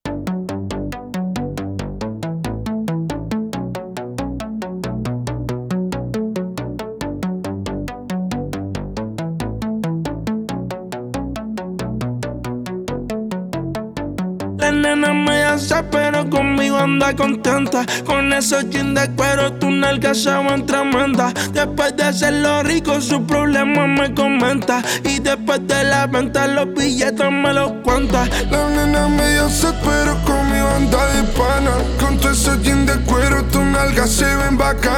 Страстные ритмы латино
Жанр: Латино